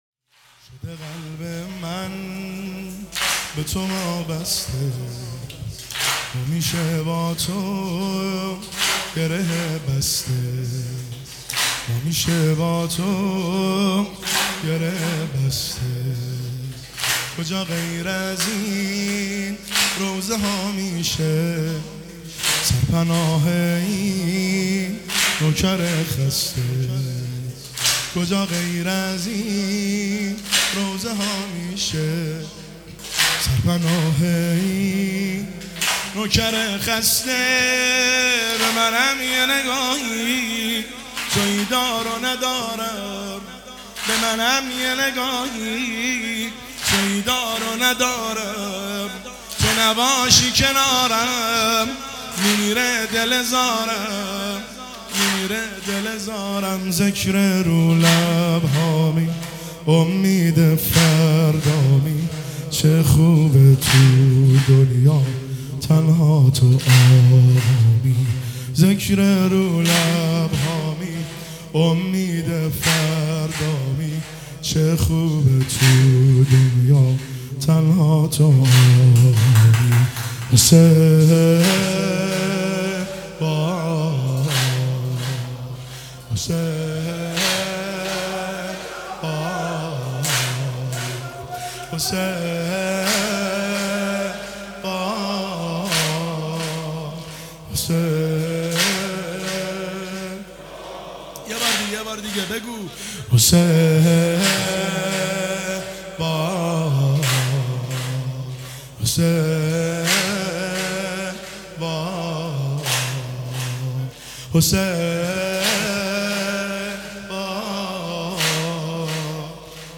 واحد  ایام فاطمیه دوم 1404